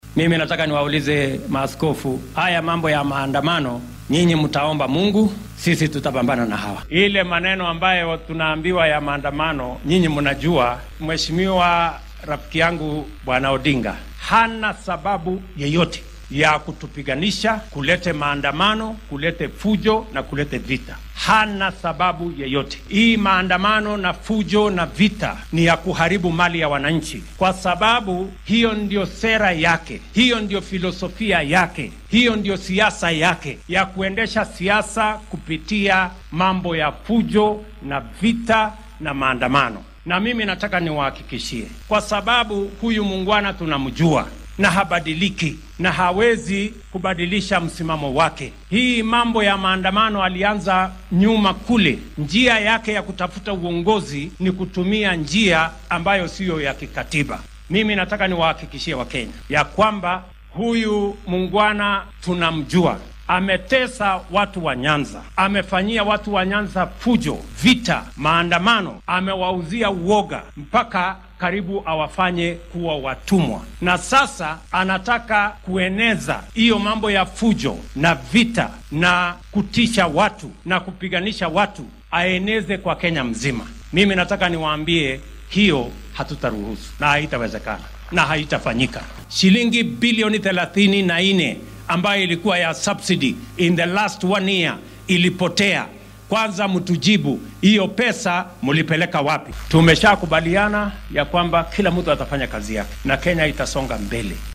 Madaxweynaha dalka William Ruto ayaa xilli uu ku sugnaa deegaanka Kapsabet ee ismaamulka Nandi waxaa uu ka hadlay mudaharaadyada ay siyaasiyiinta mucaaradka
DHAGEYSO:Madaxweynaha dalka oo si adag uga hadlay dibadbaxa ay mucaaradka qorsheynayaan